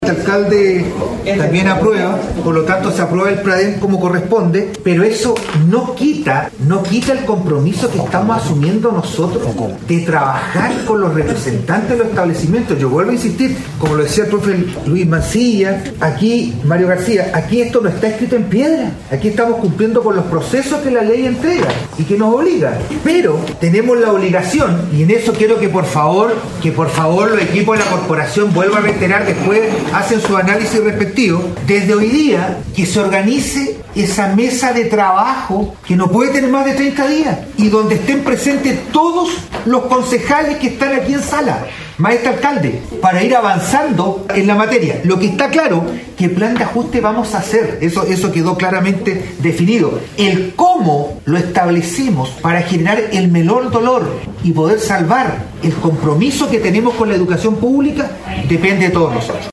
Respecto a esta aprobación el alcalde de la capital provincial de Chiloé, Juan Eduardo Vera, indicó que, no obstante esta aprobación, se realizara un plan de ajuste para poder salvaguardar la educación pública comunal: